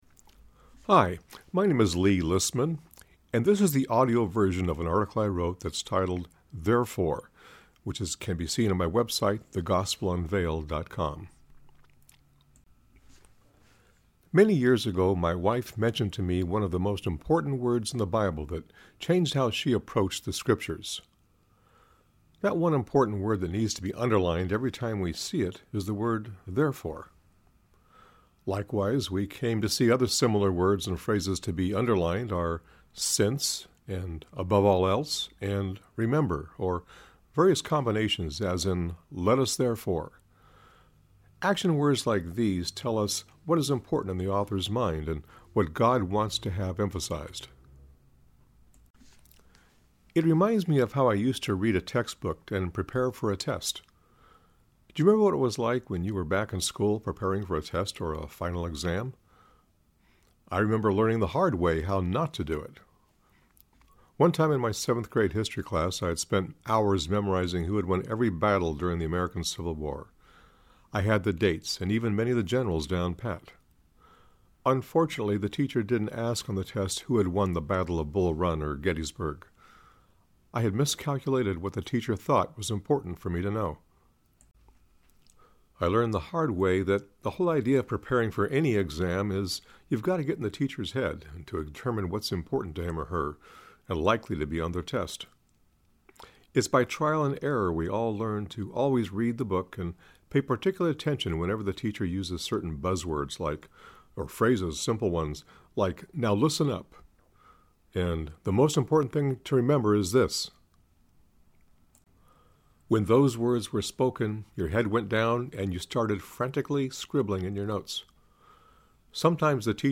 (Click Here if you wish to listen to the article read by myself)